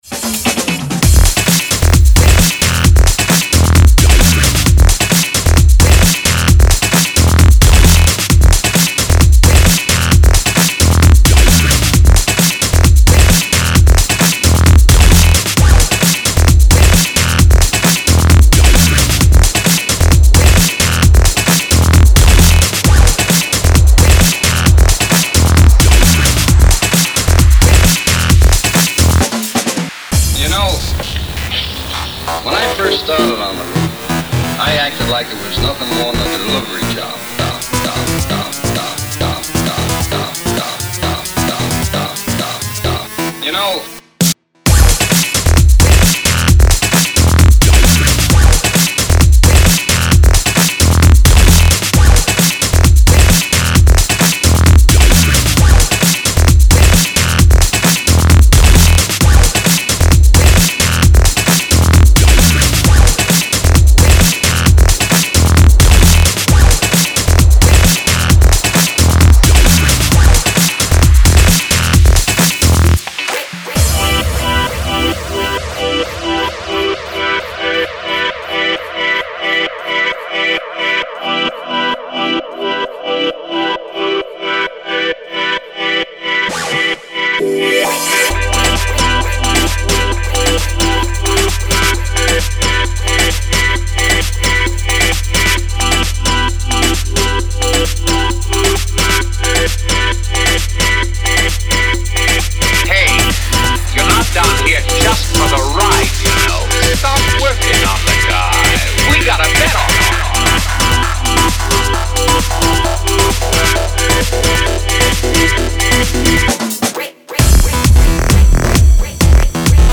Funky Breaks